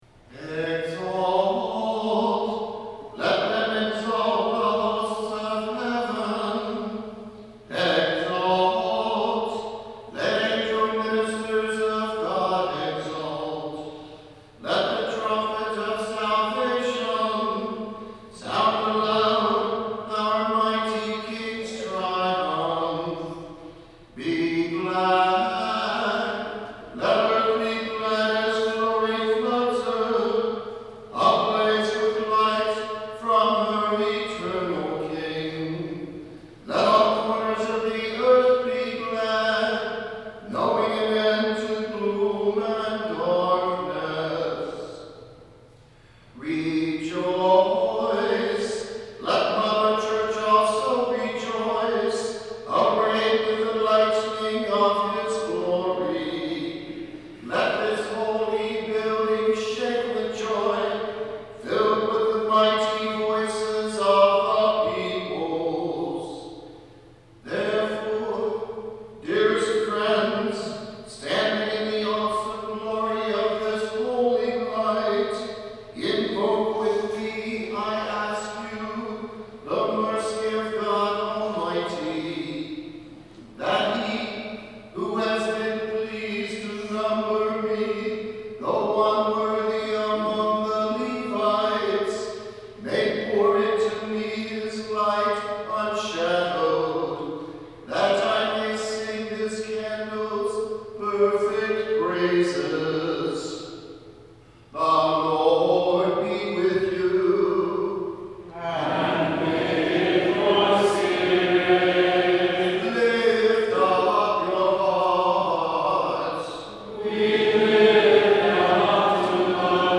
Lakewood Cluster Choirs of Saint Clement, Saint James and Saint Luke Sang this Song
2023 Easter Vigil